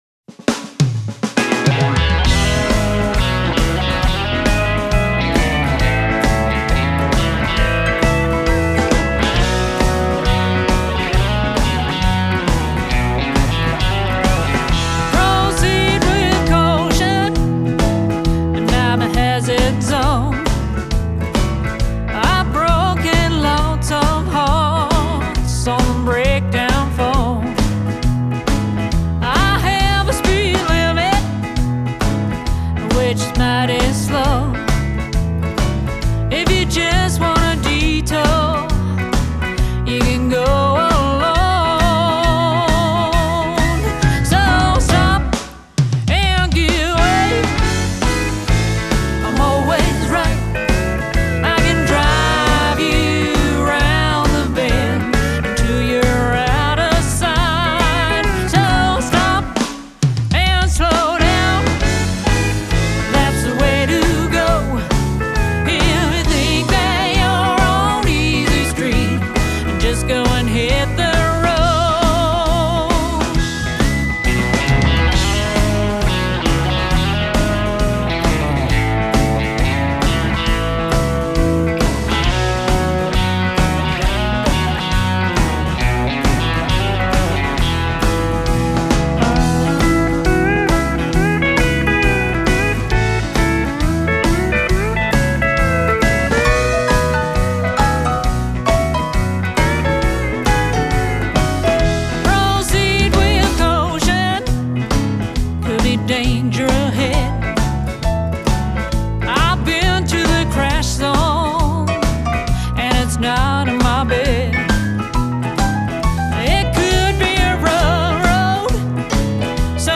fun upbeat country swing style song